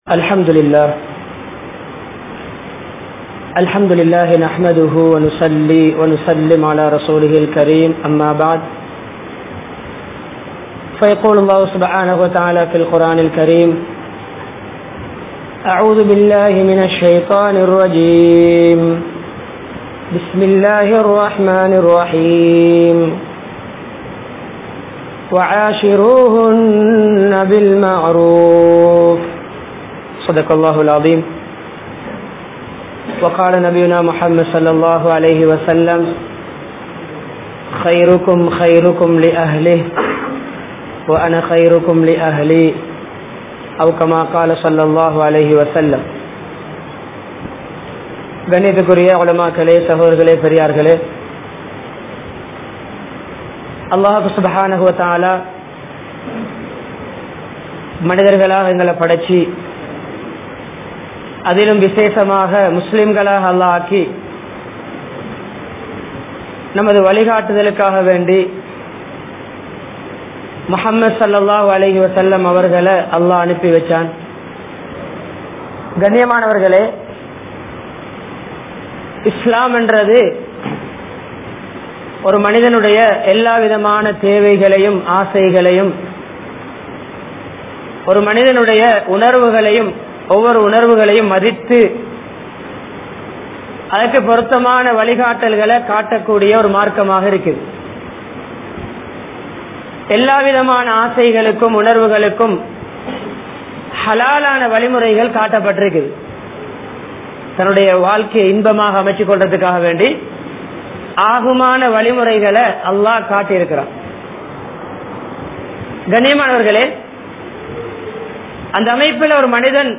Pirarin Kudmbaththai Pirikkaatheerhal (பிறரின் குடும்பத்தைப் பிரிக்காதீர்கள்) | Audio Bayans | All Ceylon Muslim Youth Community | Addalaichenai
Colombo 12, Aluthkade, Muhiyadeen Jumua Masjidh